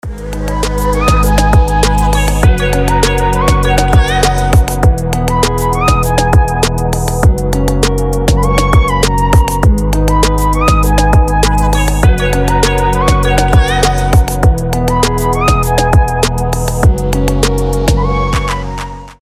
• Качество: 320, Stereo
милые
басы
качающие
Chill Trap
детский голос
Миленький такой проигрыш